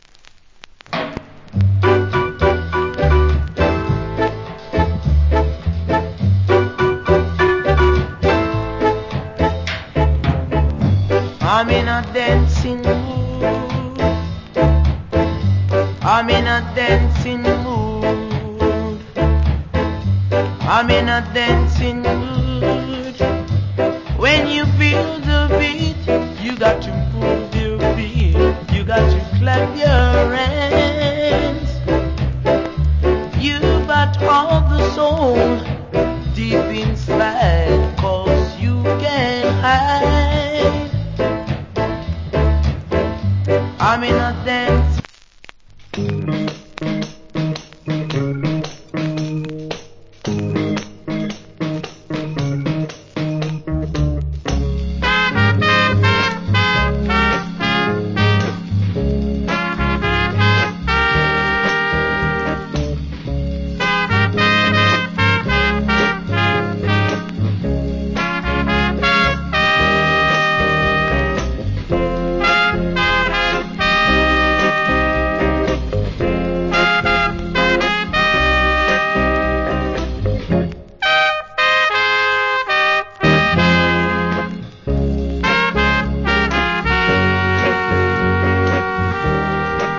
Cool Rock Steady Vocal.